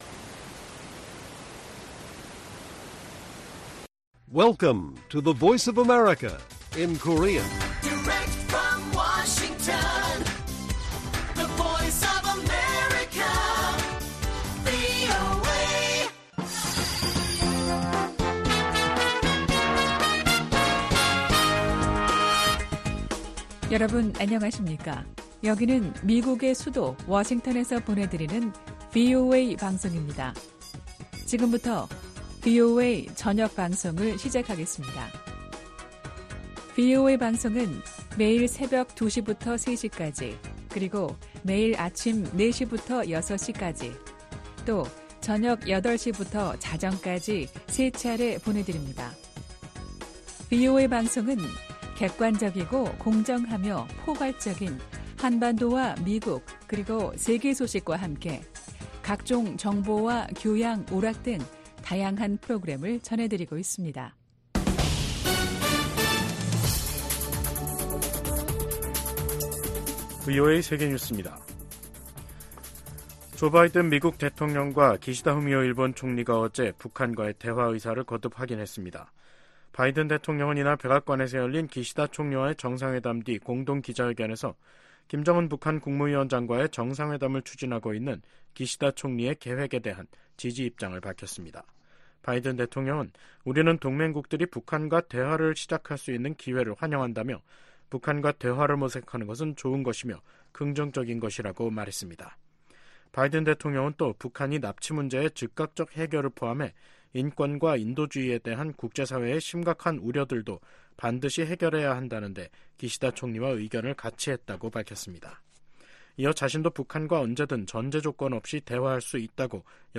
VOA 한국어 간판 뉴스 프로그램 '뉴스 투데이', 2024년 4월 11일 1부 방송입니다. 조 바이든 미국 대통령과 기시다 후미오 일본 총리가 정상회담을 열고 북한 문제와 군사 구조 재편 방안 등을 논의했습니다. 미국 상원과 하원에서 일본 총리의 미국 방문을 환영하는 결의안이 발의됐습니다. 어제 치러진 한국의 국회의원 총선거가 야당의 압승으로 끝난 가운데 탈북민 출신 4호 의원이 당선됐습니다.